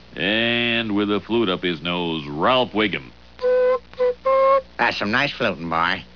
Ralph Wiggum playing the flute at church (the flute is shoved up his nose)
flute.wav